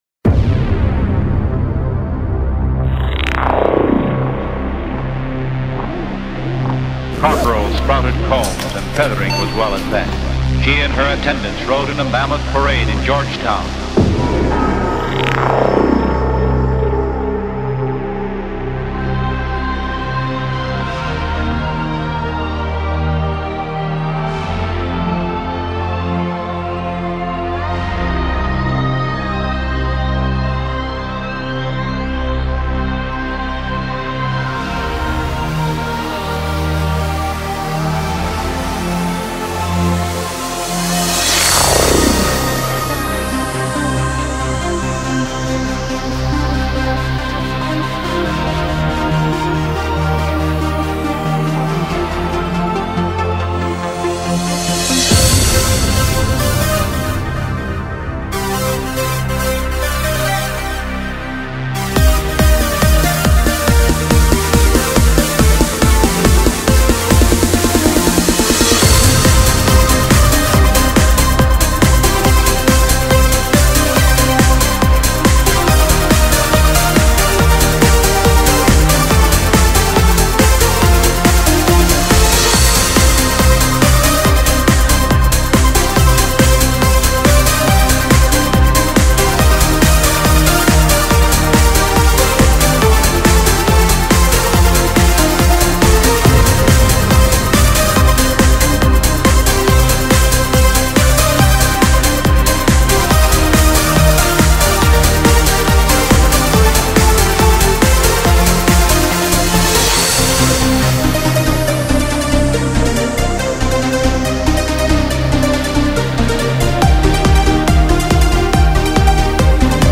Genre: Synthspace, Electronic.